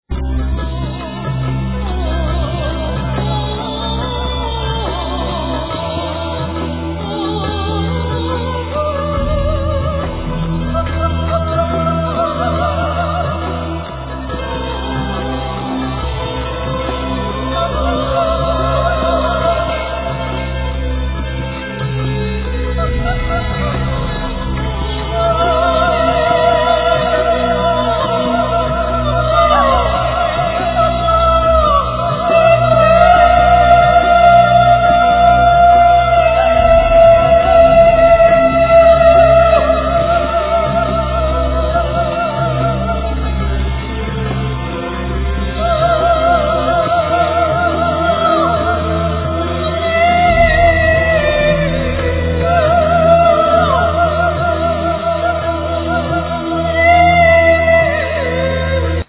Yang T'Chin,Santoor,Rhythm,Voice
Voices, Snare drums, Percussions
Keyboards, Timpani, Shamanic Drums, Percussions
Voices, Timpani, Singing bows, Percussions